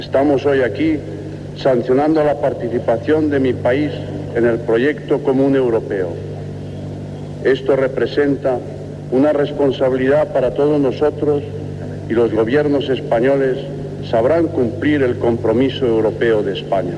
Discurs del rei Juan Carlos I el dia de l'adhesió d'Espanya a la Comunitat Europea.
Informatiu
Extret de Crònica Sentimental de Ràdio Barcelona emesa el dia 12 de novembre de 1994.